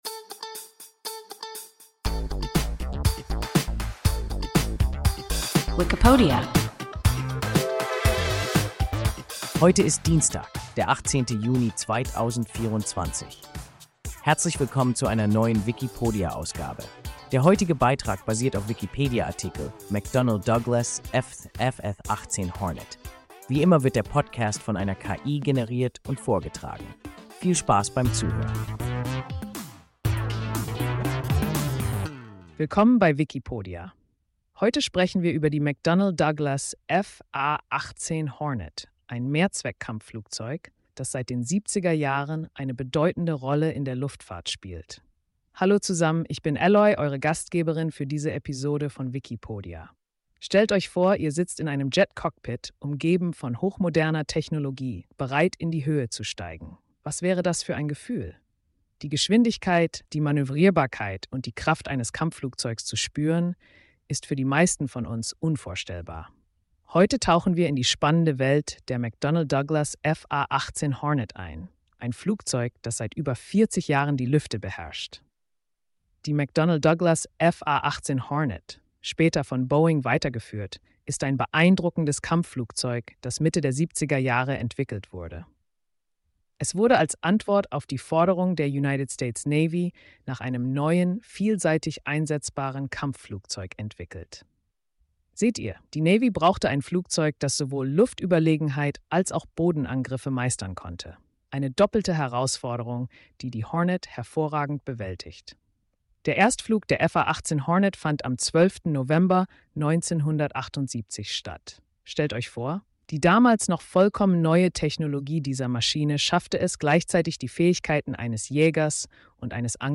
McDonnell Douglas F%2FA-18 Hornet – WIKIPODIA – ein KI Podcast